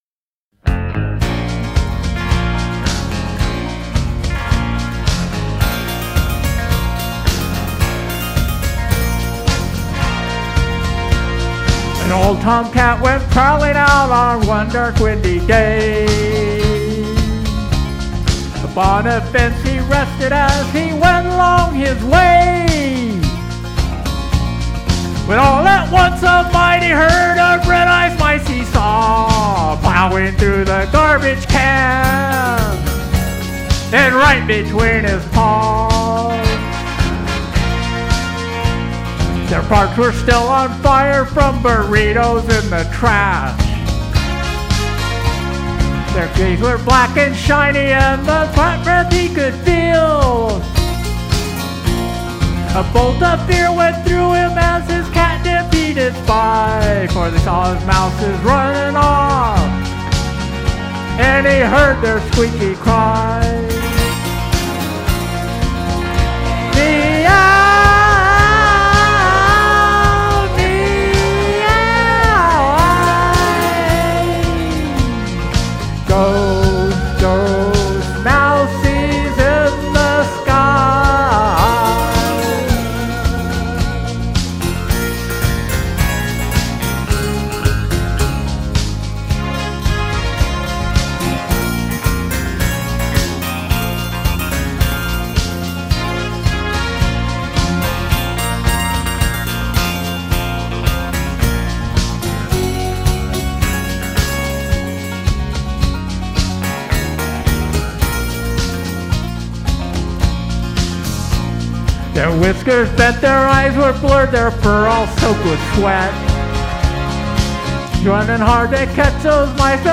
Parody lyrics
Vocals